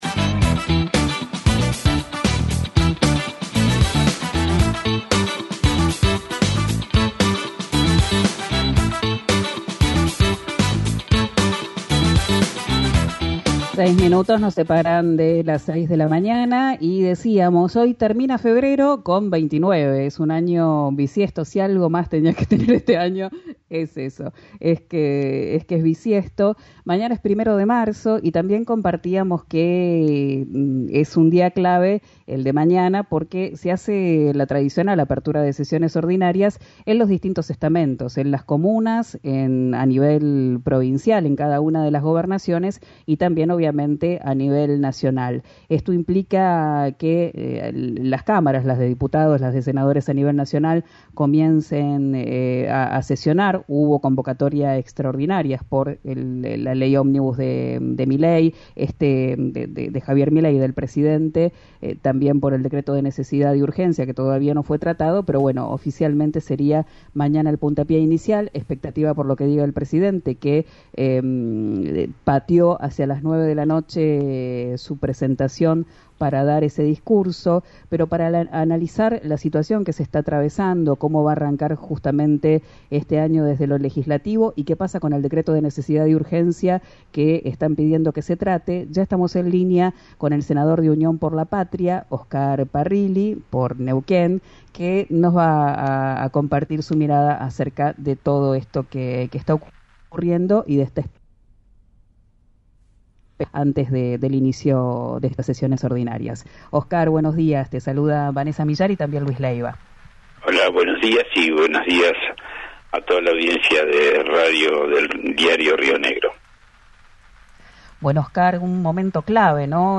En comunicación con RÍO NEGRO RADIO, el referente de Unión por la Patria arremetió contra la ministra Patricia Bullrich y le pidió autocrítica al peronismo.
Escuchá al senador Oscar Parrilli en RÍO NEGRO RADIO: